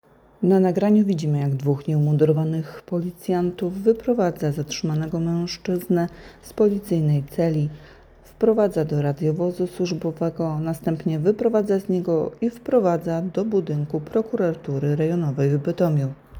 Nagranie audio Audiodeskrypcja